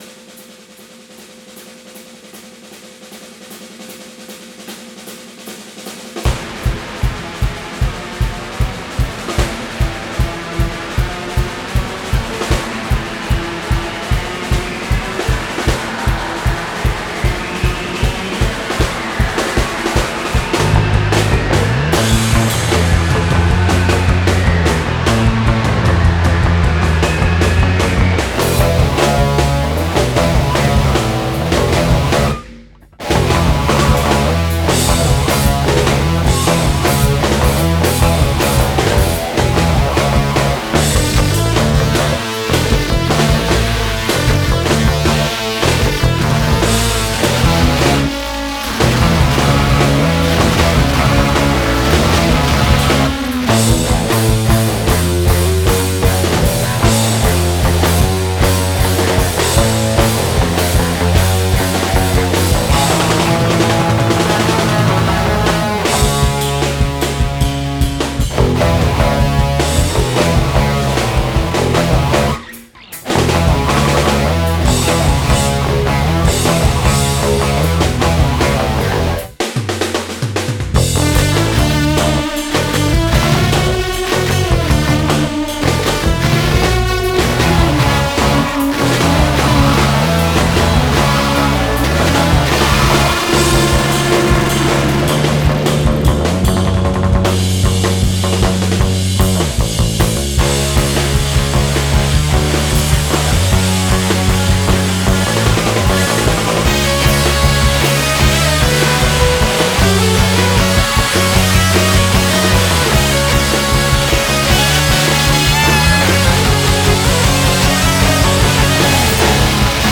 drums
guitars